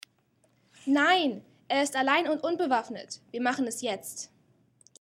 REMARKABLY NEATO GERMAN PASSAGES